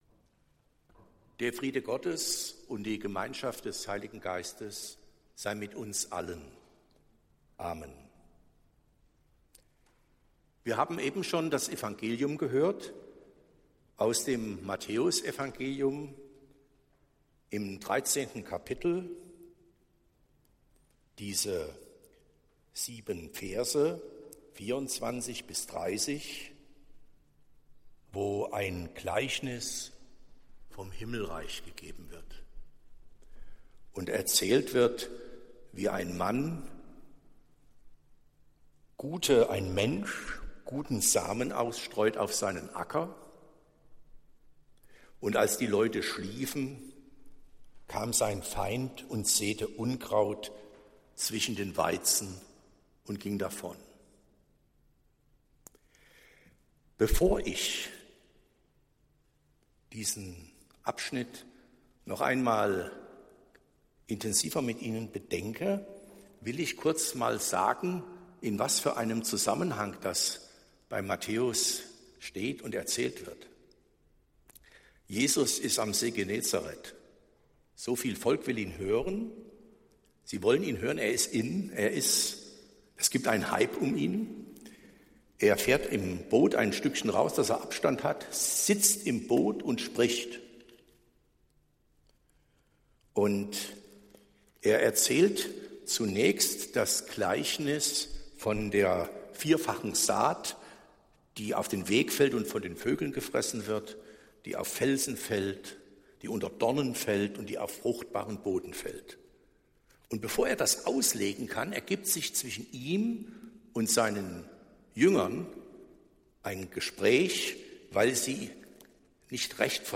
Predigt des Gottesdienstes vom Altjahrsabend aus der Zionskirche